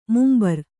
♪ mumbar